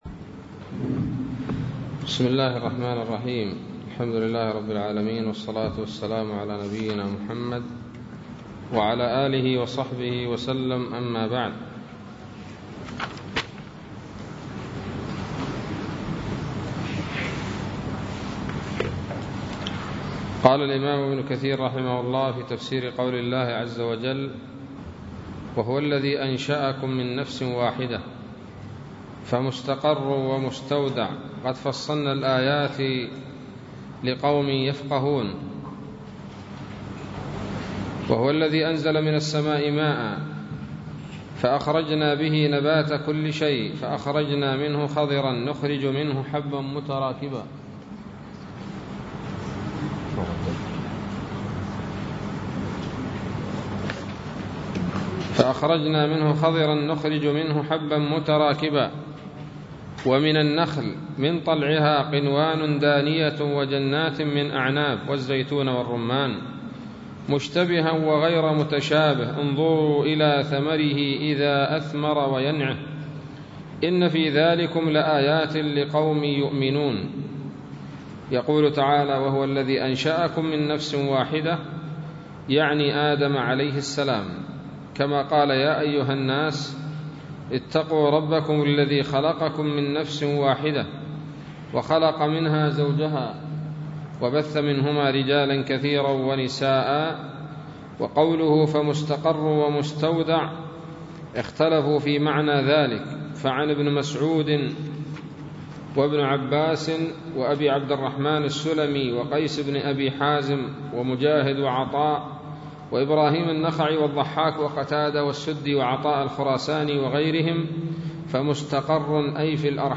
006 سورة الأنعام الدروس العلمية تفسير ابن كثير دروس التفسير